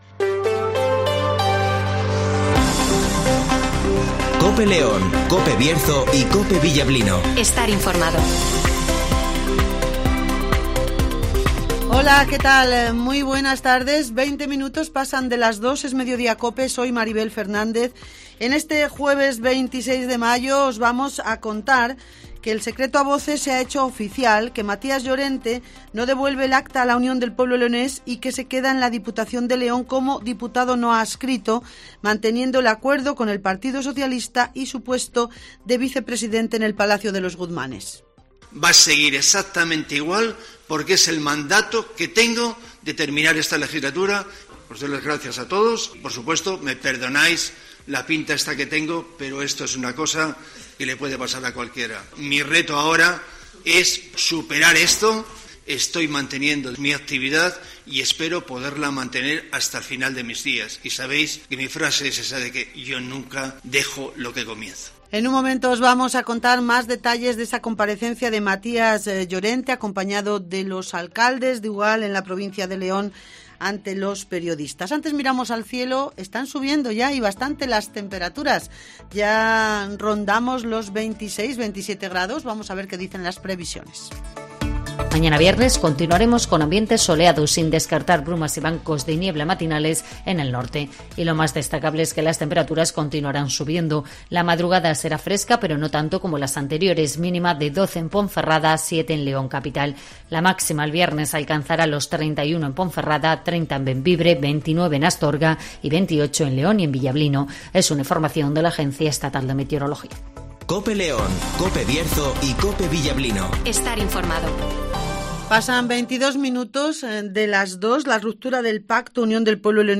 - Matías Llorente ( Diputado no Adscrito )
- Olegario Ramón ( Alcalde de Ponferrada )
- Niños en " Cuentacuentos "